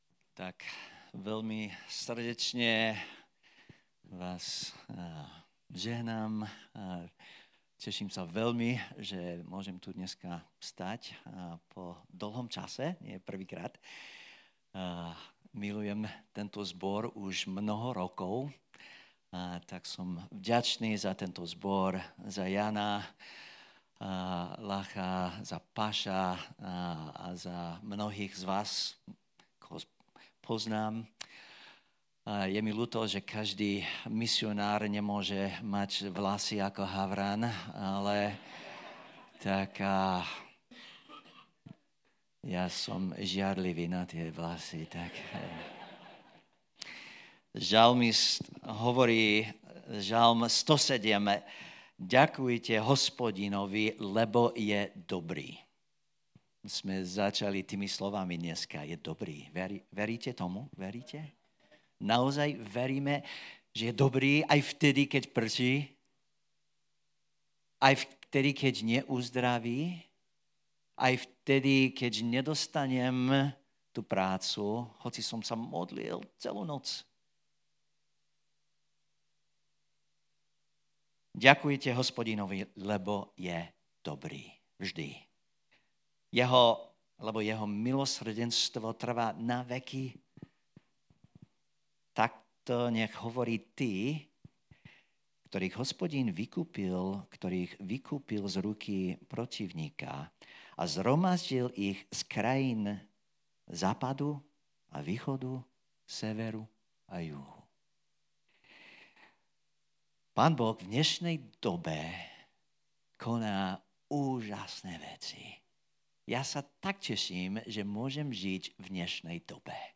Vypočuj si túto inšpirujúcu kázeň a nechaj Svätého Ducha hovoriť o tvojom mieste v Božej misii.